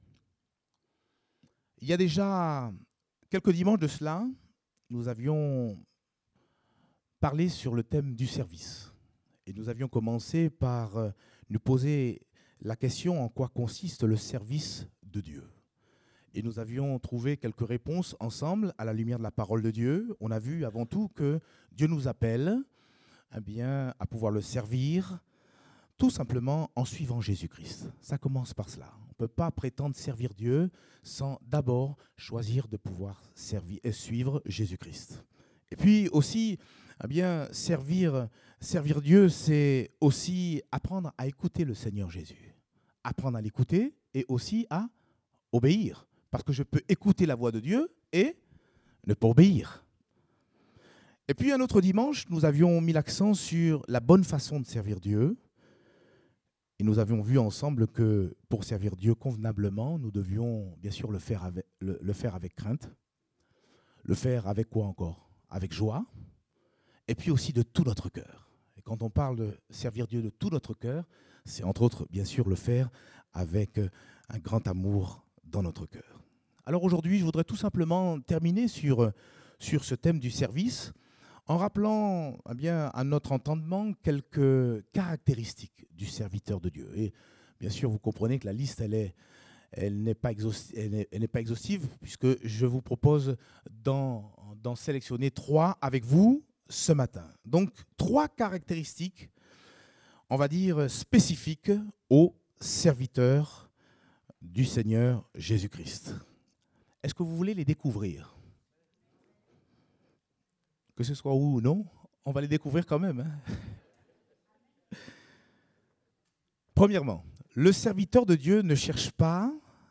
Date : 19 novembre 2017 (Culte Dominical)